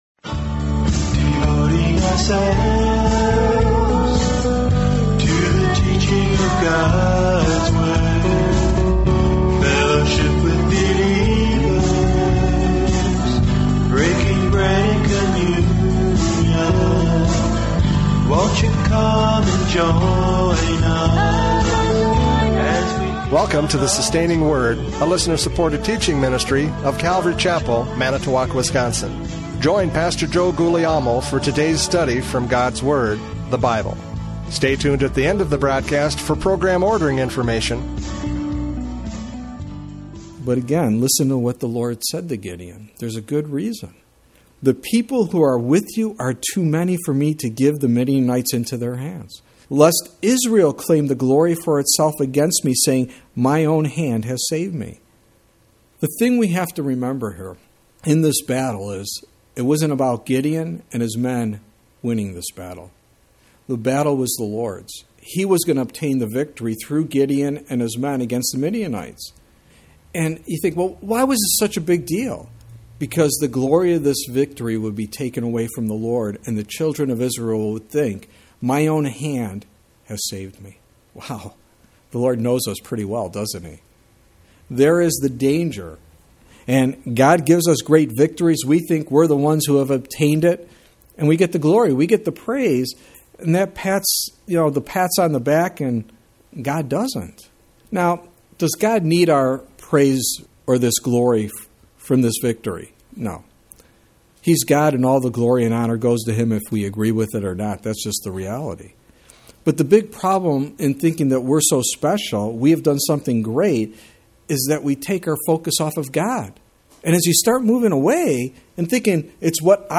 Judges 7:1-7 Service Type: Radio Programs « Judges 7:1-7 Are You Kidding Me?